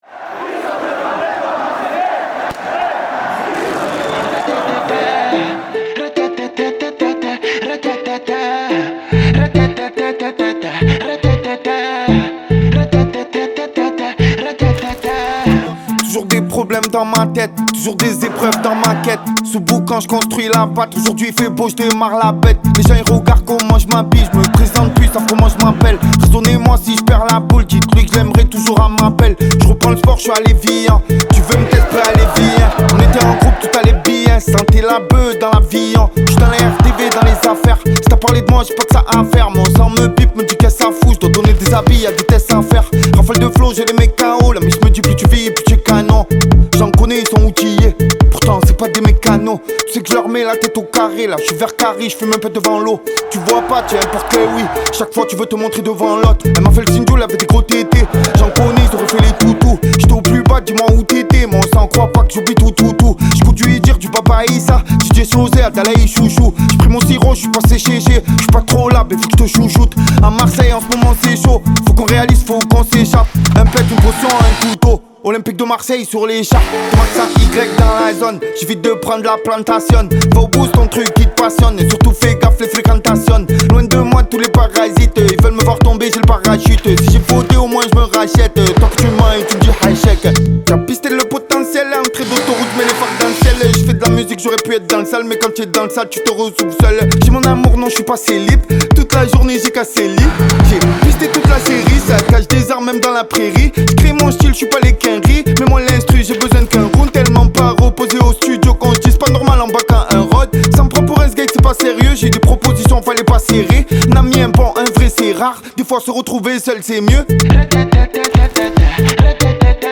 french rap, pop urbaine Écouter sur Spotify